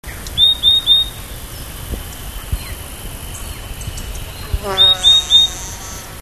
Sounds of birds in Suriname
Finsch's EuphoniaEuphonia finschi